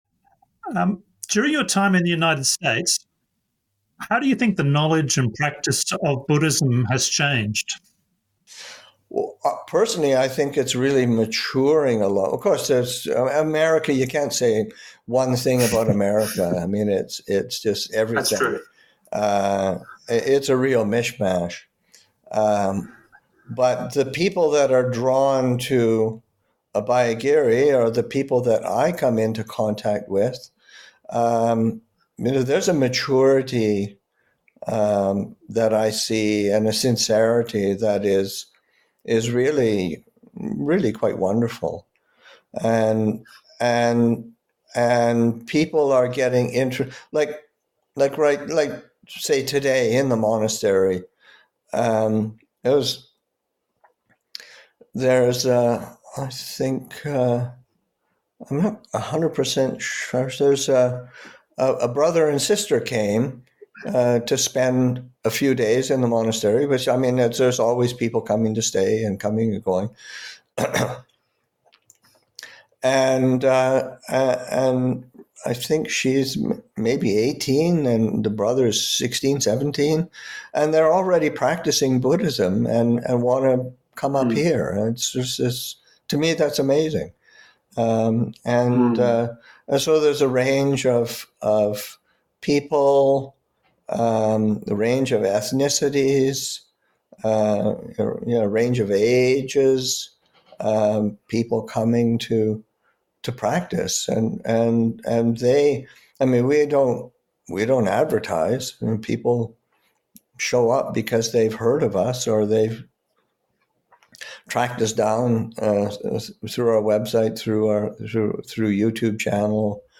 Online interview